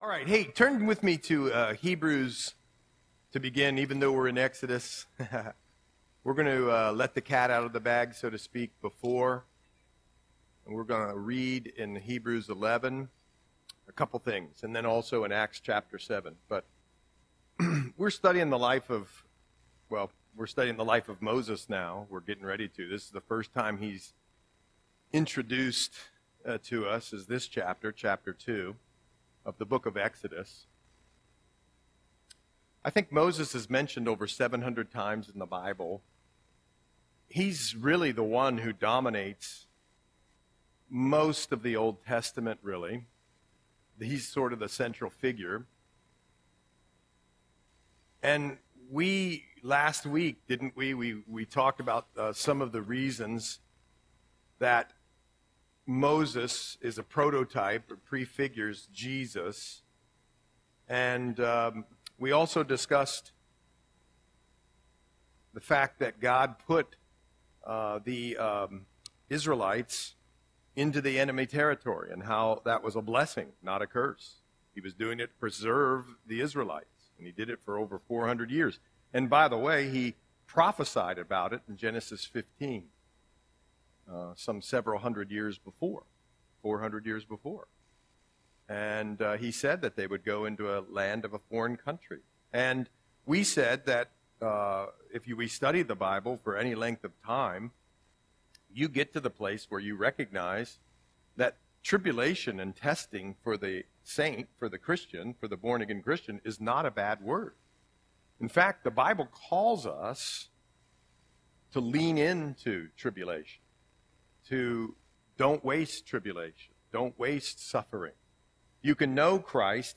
Audio Sermon - October 16, 2024